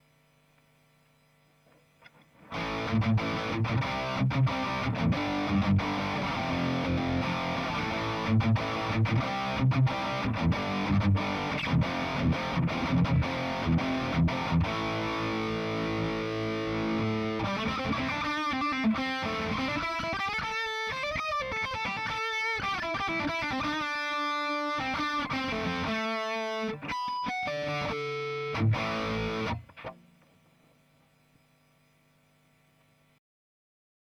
Marshall_drive_laney.wav - ��������, ��� ���� ��� ������ � �������, ������� � �����.